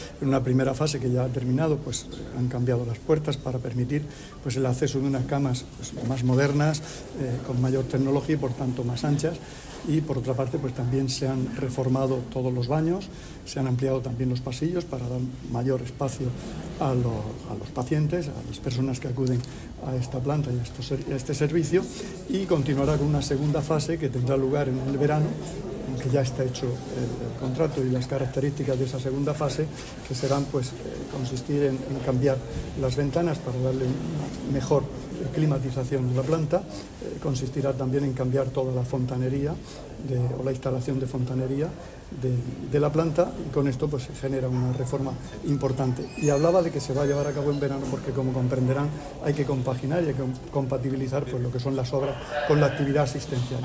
Sonido/ Declaraciones del consejero de Salud sobre las obras de remodelación en la planta de Maternidad del hospital Rafael Méndez.